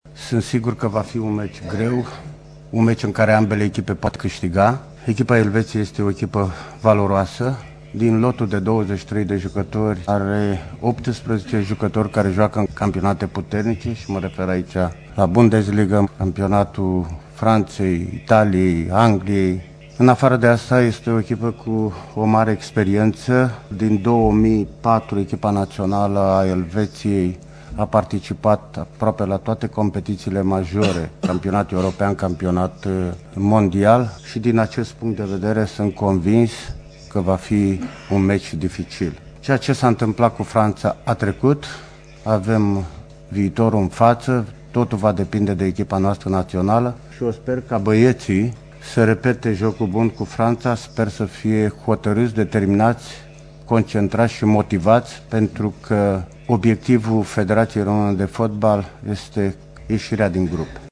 De altfel, selecţionerul României, Anghel Iordănescu, a subliniat, la conferinţa de presă de aseară, de la Paris, că obiectivul impus de Federaţie e ieşirea din grupe.
Iordanescu-despre-meciul-cu-Elvetia.mp3